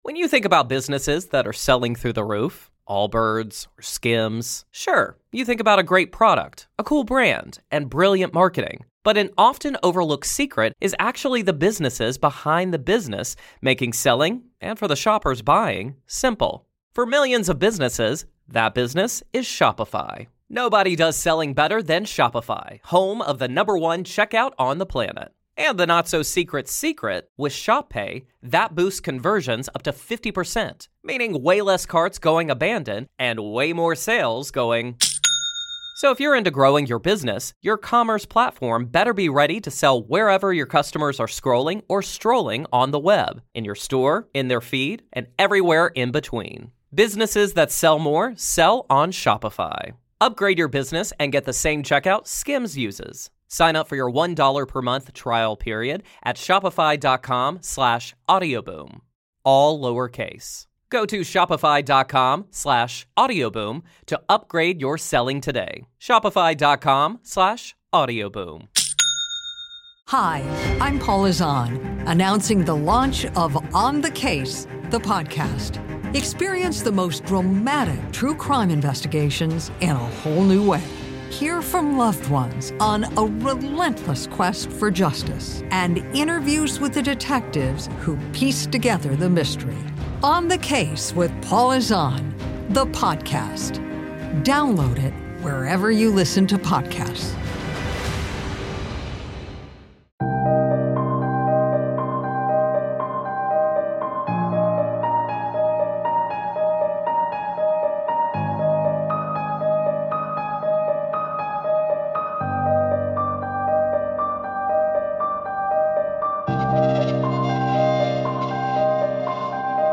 True paranormal stories of ghosts, cryptids, UFOs and more, told by the witnesses themselves.